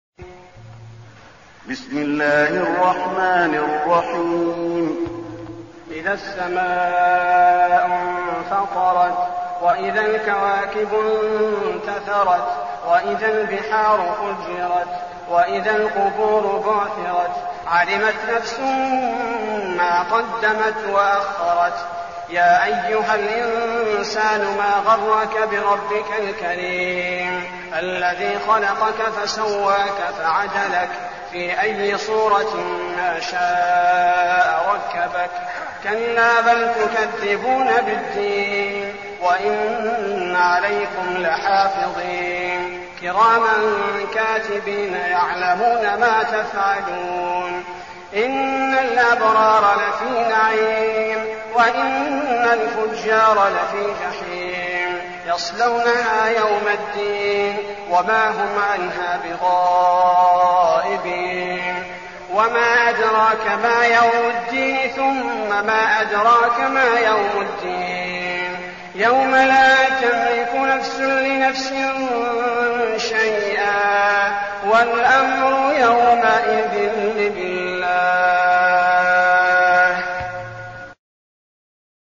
المكان: المسجد النبوي الانفطار